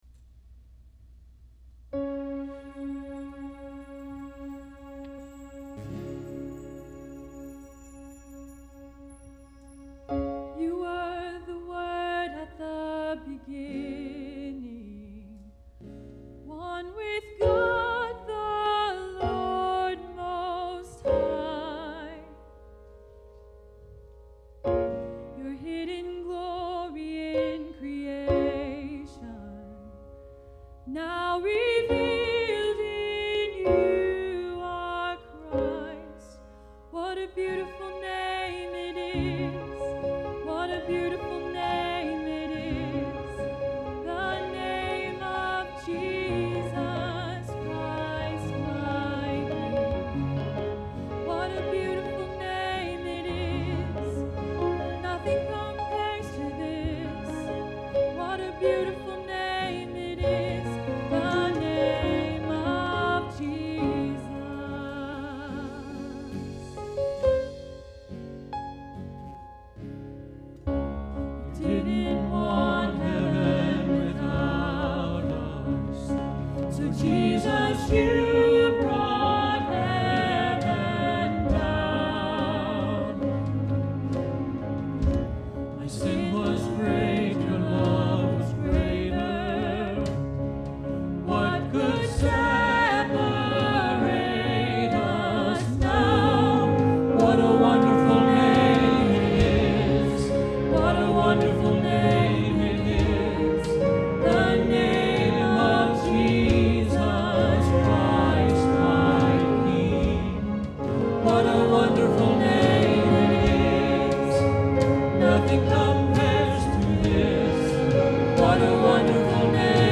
Exodus 17:1-6 Service Type: Traditional Service Bible Text